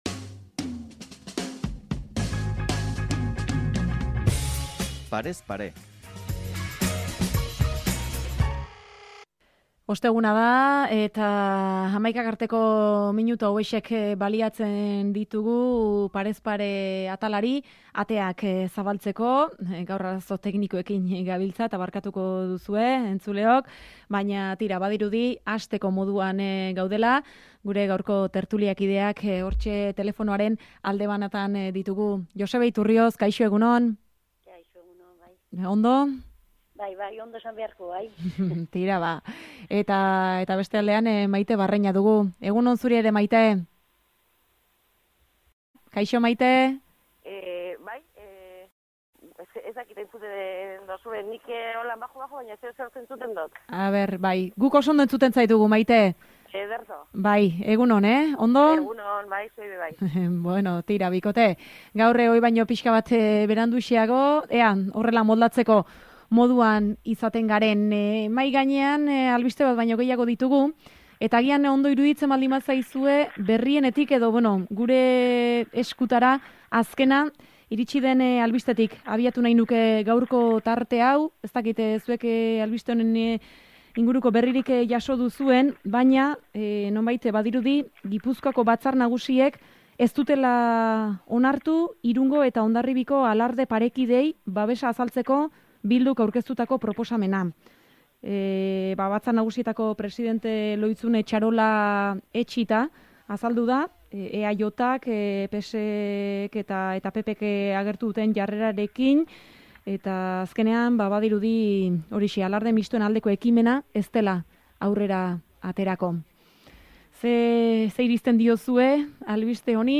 Atalaren lehen zatian gaur, tertuliarako tartea hartu dugu